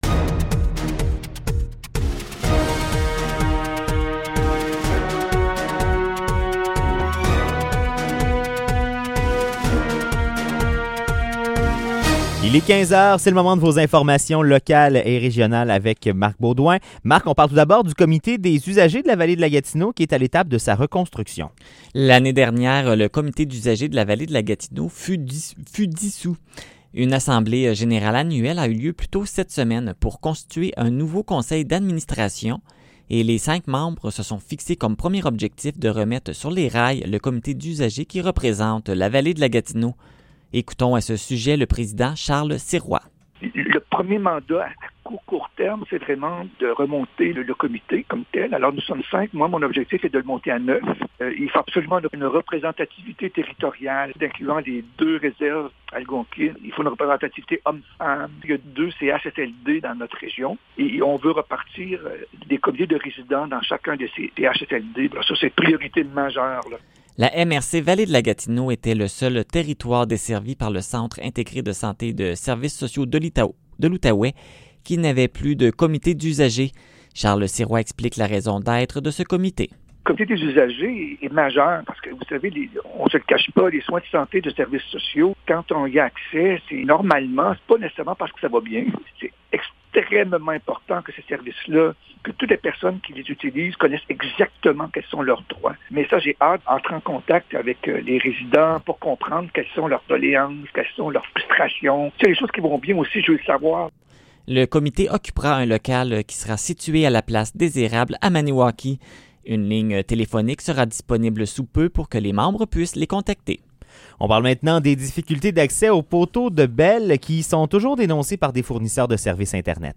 Nouvelles locales - 27 novembre 2020 - 15 h